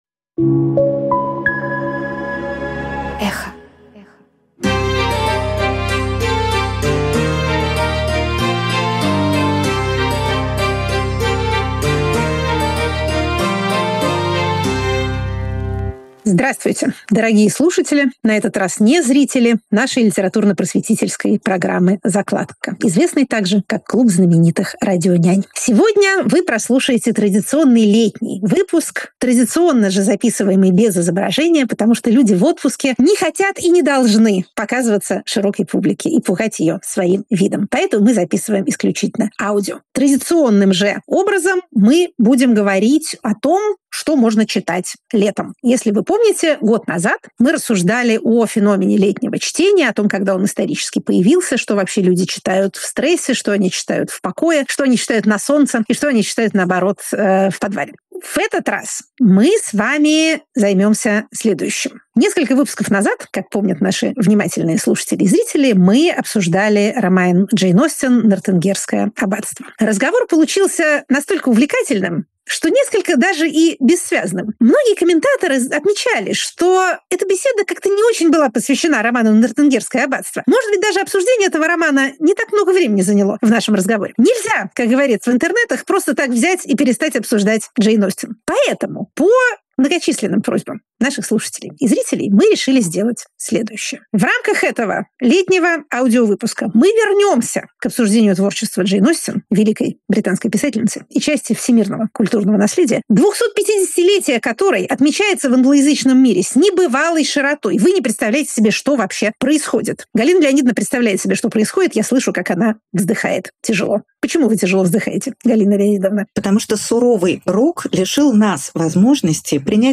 Галина Юзефович литературный критик Екатерина Шульман политолог